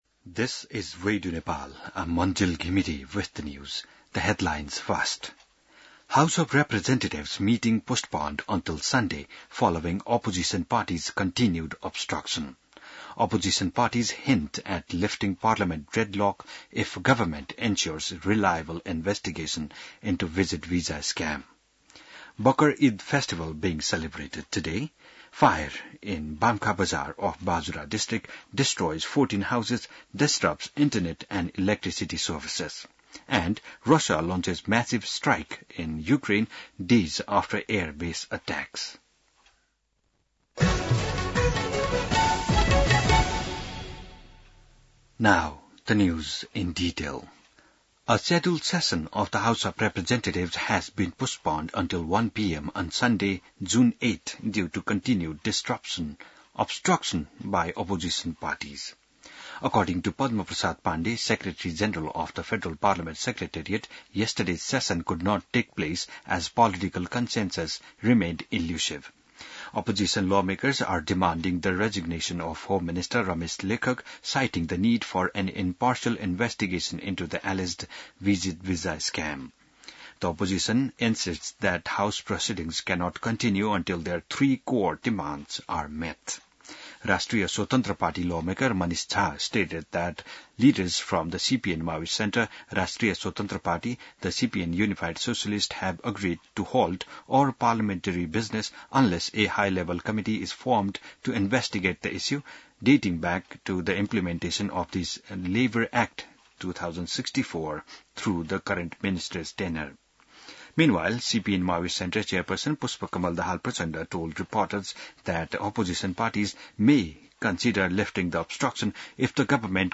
बिहान ८ बजेको अङ्ग्रेजी समाचार : २४ जेठ , २०८२